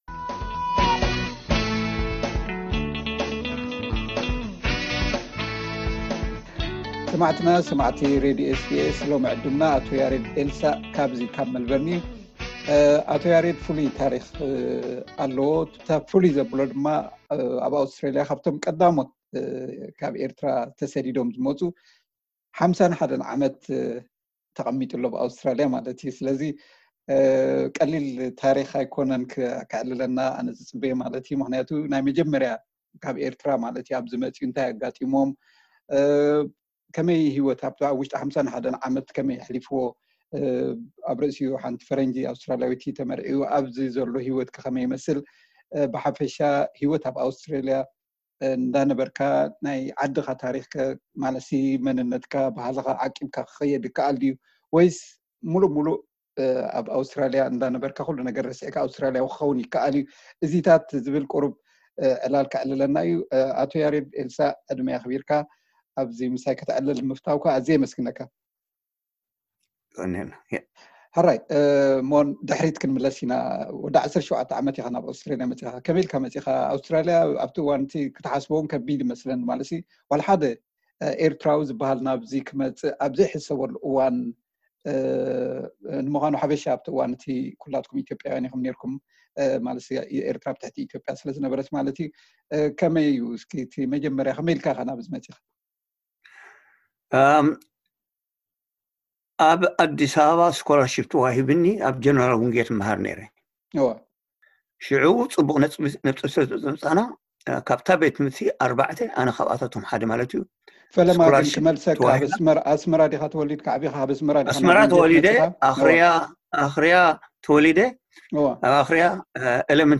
ቃለ መሕትት ደጊምና ነቕርቦ ኣለና።